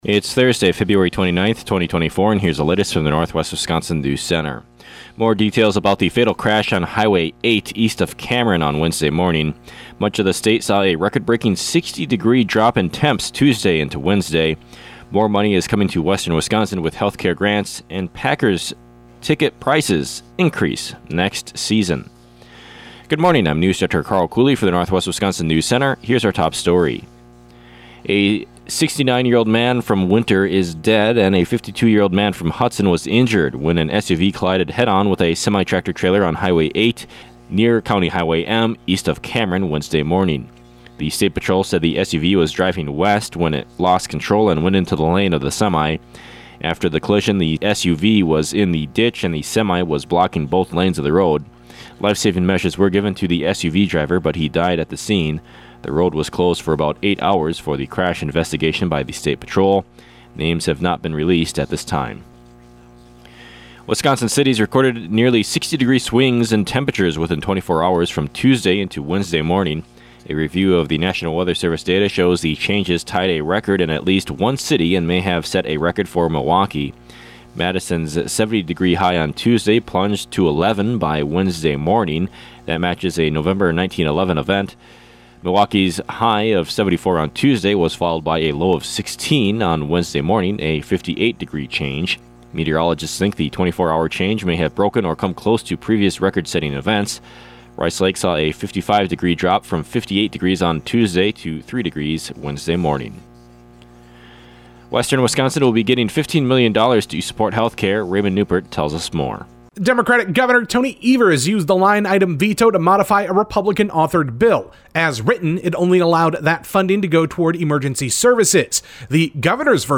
AM NEWSCAST – Thursday, Feb. 29, 2024 | Northwest Builders, Inc.